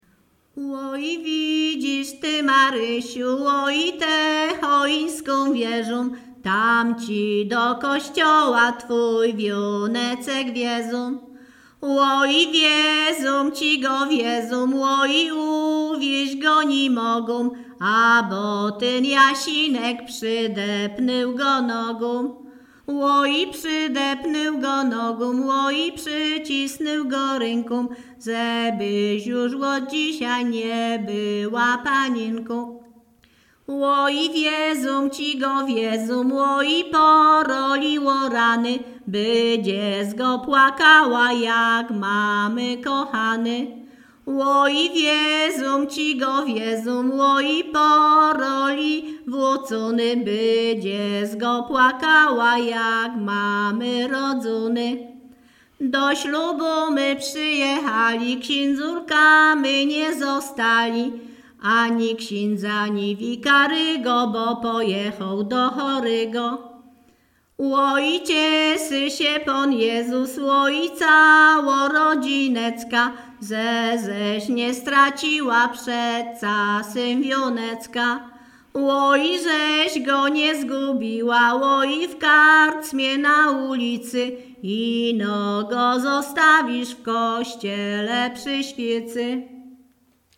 Śpiewaczki z Chojnego
województwo łódzkie, powiat sieradzki, gmina Sieradz, wieś Chojne
Weselna
liryczne miłosne weselne wesele